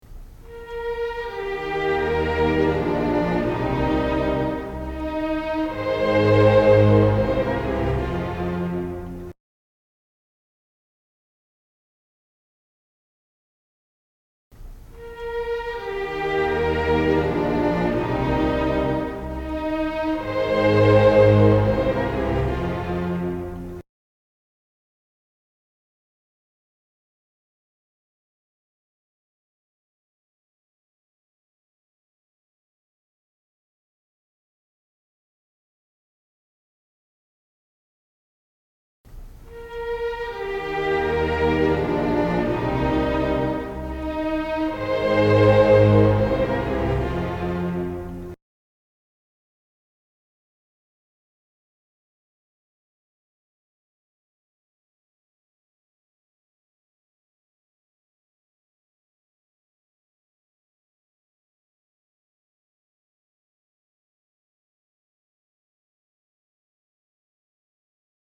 Du får höra varje exempel tre gånger efter varandra med allt längre tid emellan.